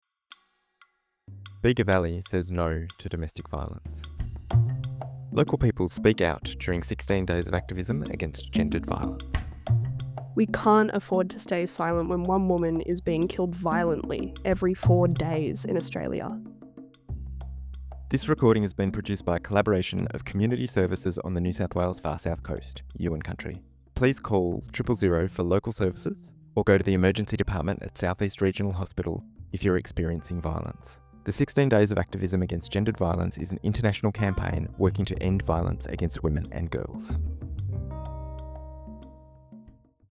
This year, the Bega Valley Domestic Violence & Sexual Assault Committee collected 25 statements from Bega Valley Community members, men and women from all walks of life, calling on all of us to do our part to stop Gender-Based Violence.
As part of this campaign, we collected brief audio statements from local Bega Valley community members to raise awareness about domestic, family, and sexual violence.